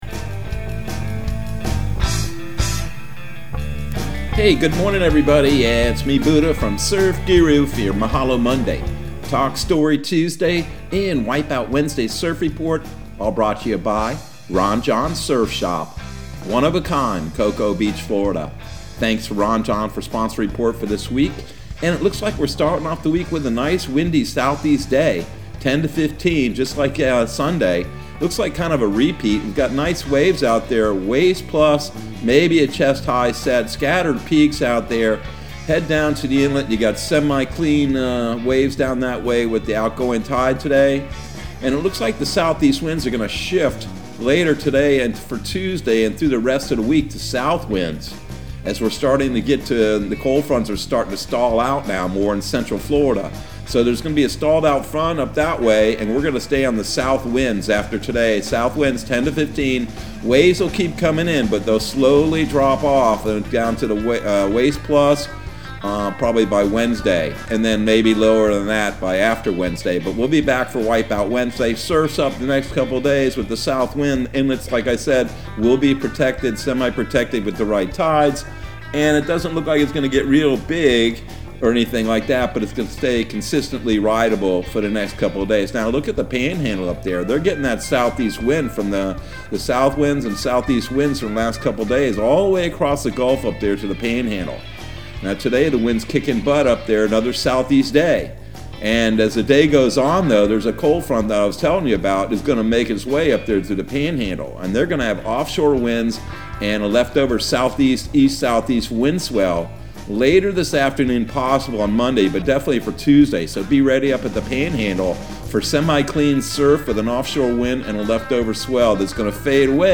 Surf Guru Surf Report and Forecast 03/07/2022 Audio surf report and surf forecast on March 07 for Central Florida and the Southeast.